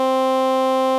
使用软件生成一段反锯齿波音调，频率为262Hz：
然后使用参数滤波器叠加上600、1100、3200、3600Hz四个共振峰：
能够听到一个非常机械的男声在唱“啊”。
【文件】人工合成的“啊”声样本
synthesized-a.wav